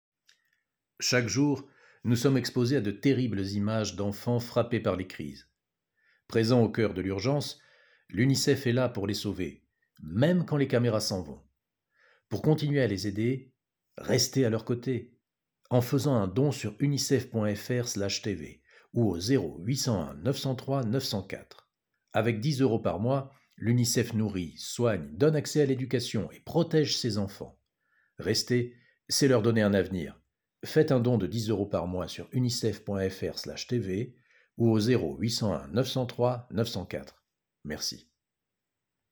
Voix off
40 - 59 ans - Baryton-basse